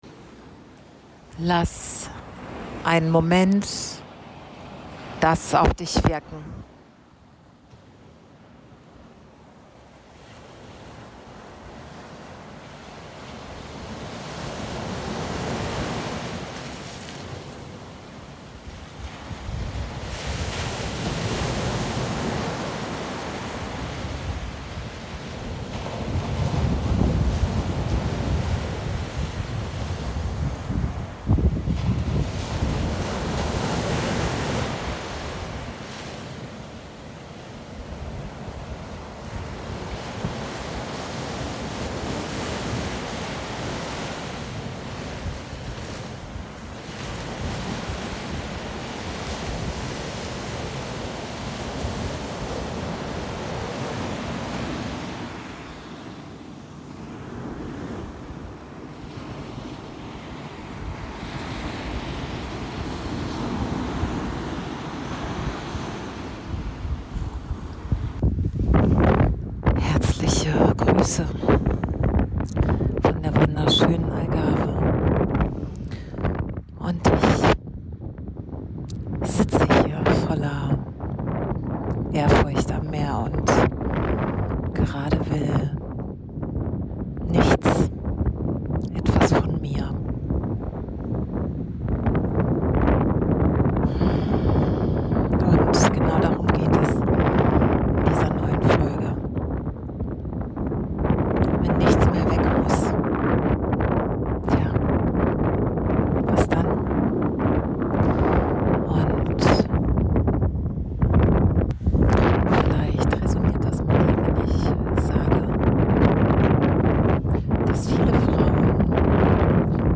Diese Folge NICHT technisch perfekte Folge ( ab Minute 7 ist es sehr viel ruhiger!!) ist kein Raum zum Besserwerden.
Diese Folge ist am Meer entstanden.